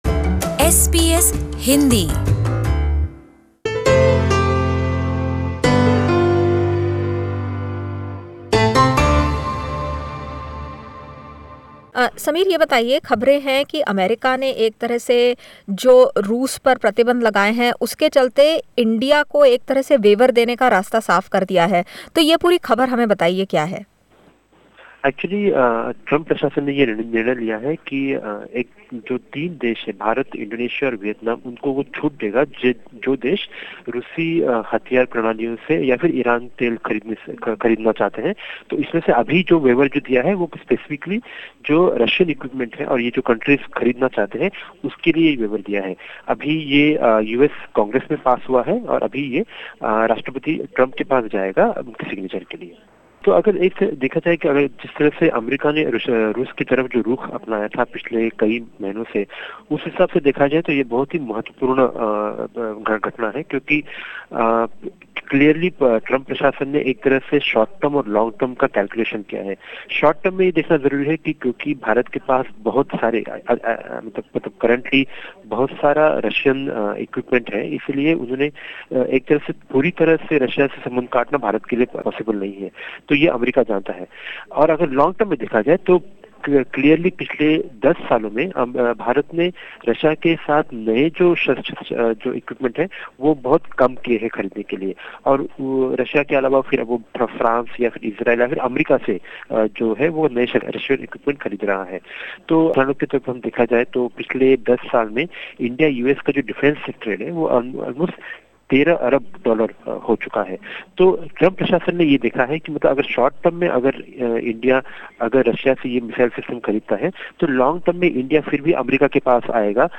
To understand the strategic significance of this move we spoke to security analyst and Director